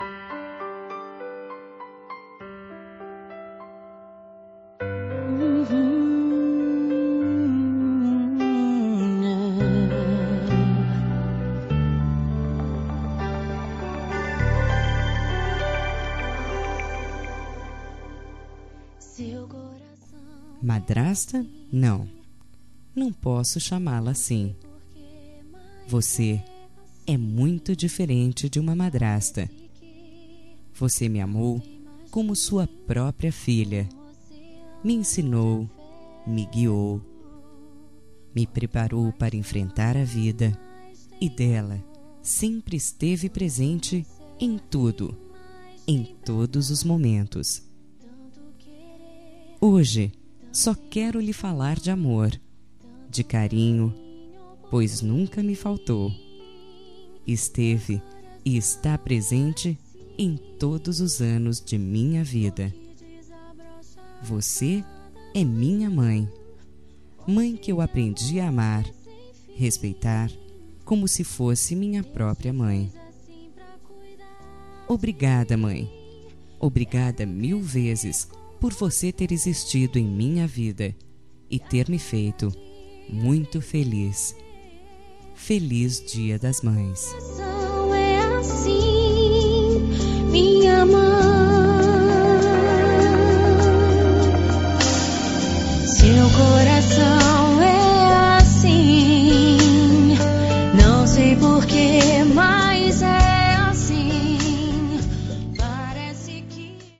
Aniversário Distante – Romântica – Masculina – Cód: 8900